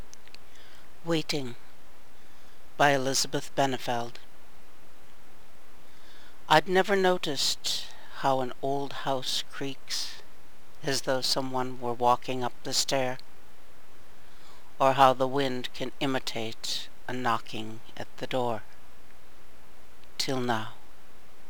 2006 Halloween Poetry Reading